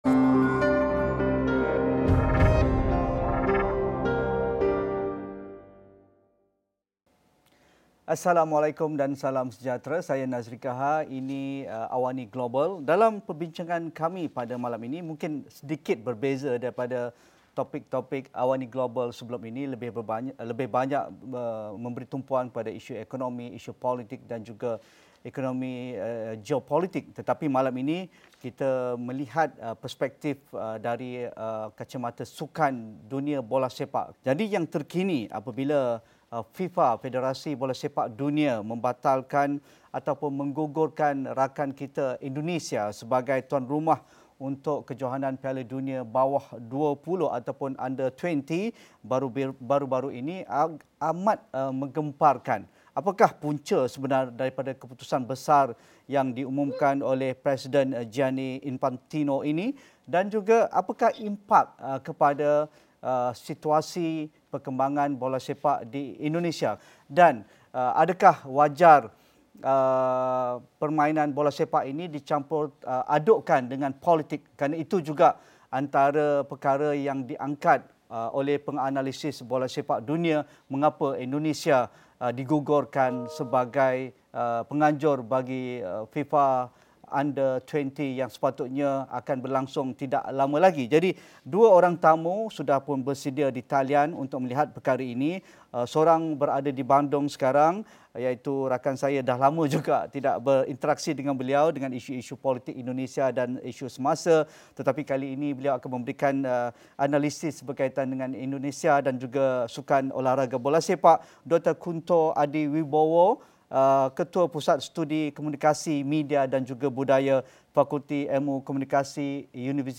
Sejauhmanakah realiti dasar sukan tanpa batasan politik? Diskusi dan analisis dalam AWANI Global, jam 9 malam.